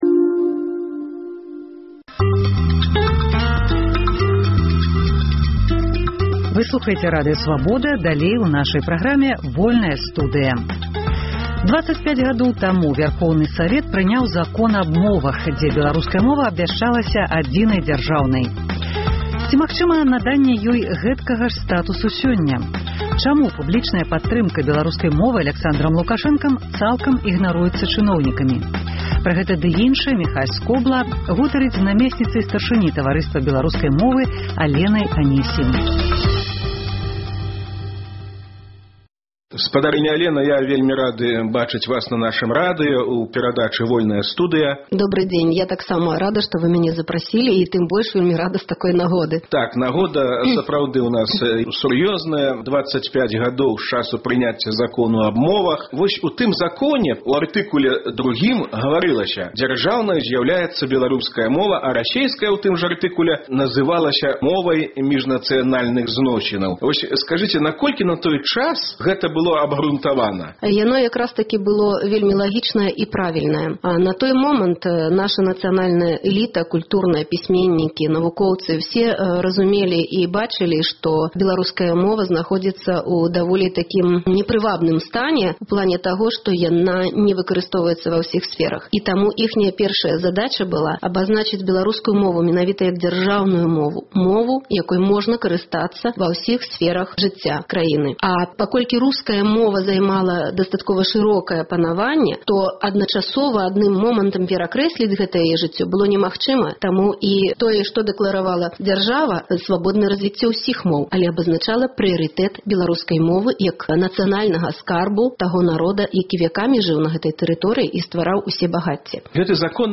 Дваццаць пяць гадоў таму Вярхоўны Савет савецкай Беларусі прыняў Закон аб мовах, дзе беларуская мова абвяшчалася адзінай дзяржаўнай. Ці магчыма наданьне ёй гэткага ж статусу ў незалежнай Рэспубліцы Беларусь? Чаму публічная падтрымка беларускай мовы Аляксандрам Лукашэнкам цалкам ігнаруецца чыноўнікамі? Госьцяй перадачы – намесьніца старшыні Таварыства беларускай мовы Алена Анісім.